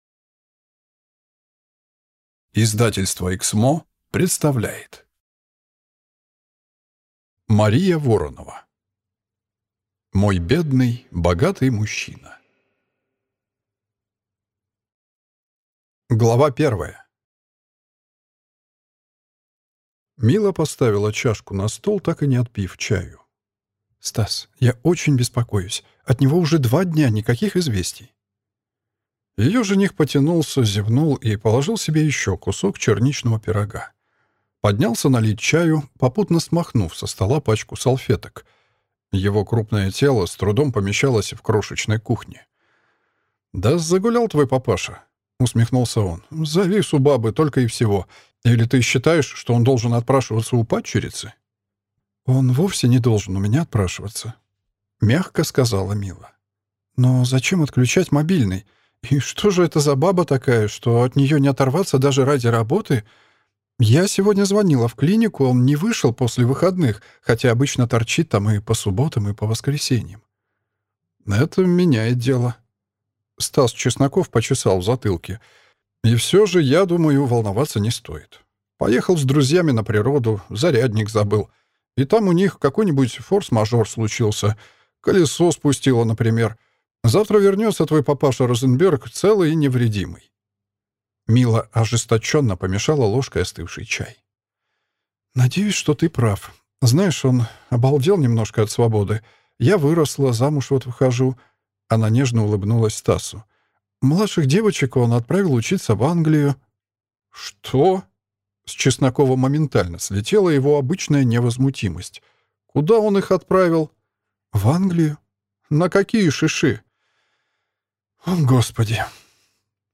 Аудиокнига Мой бедный богатый мужчина | Библиотека аудиокниг